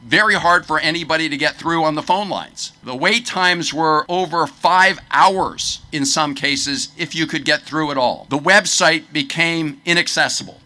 A Fells Point rally on Saturday kicked off a nationwide movement to bring attention to changes in Social Security.